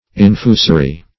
Search Result for " infusory" : The Collaborative International Dictionary of English v.0.48: Infusory \In*fu"so*ry\, a. (Zool.)